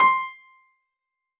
piano-ff-64.wav